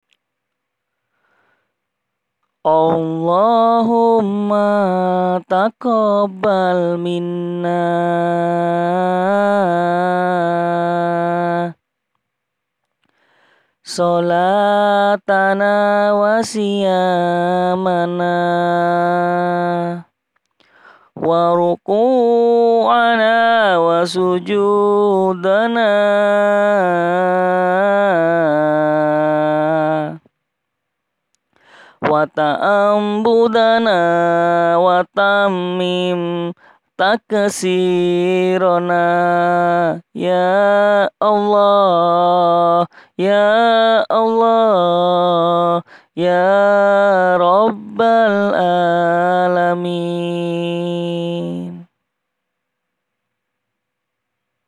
Maaf kalau suaranya jelek, ini asli saya rekam sendiri bacaan pujian yang biasanya saya pakai.
Mohon maaf sebelumnya jikalau suara saya jelek :).